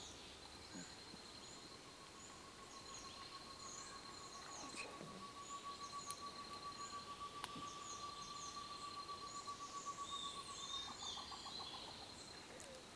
Tovaca Común (Chamaeza campanisona)
Nombre en inglés: Short-tailed Antthrush
Fase de la vida: Adulto
Localidad o área protegida: Parque Provincial Urugua-í
Condición: Silvestre
Certeza: Vocalización Grabada